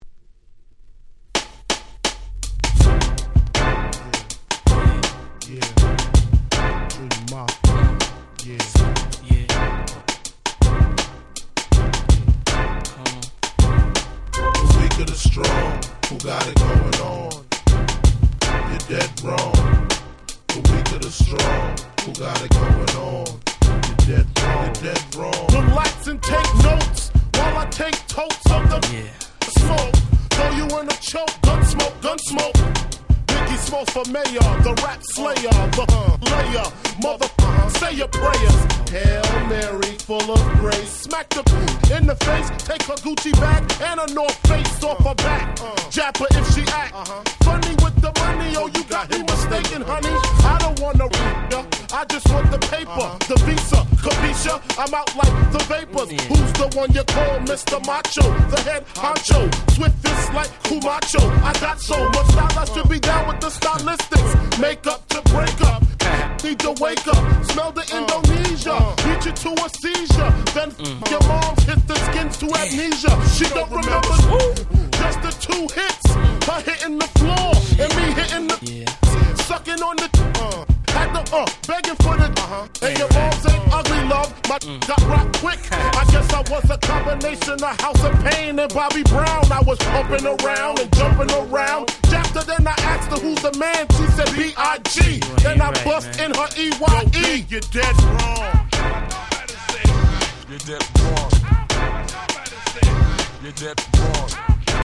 99' Super Hit Hip Hop !!